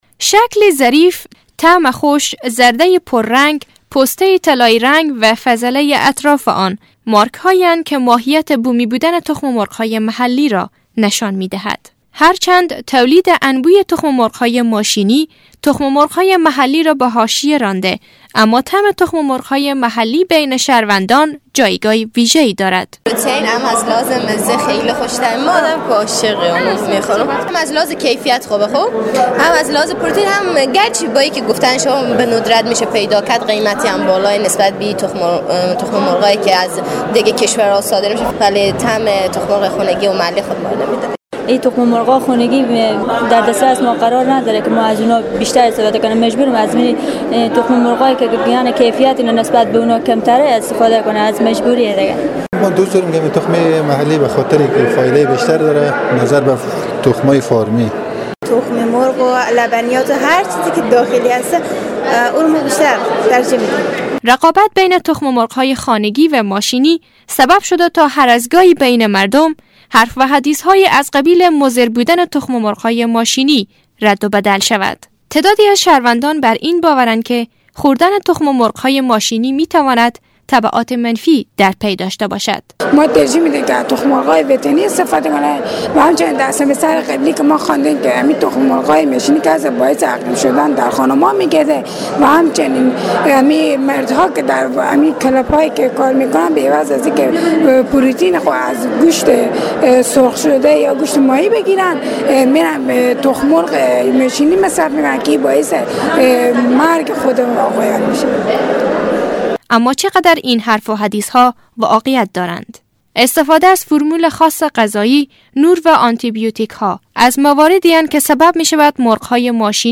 نسخه صوتی گزارش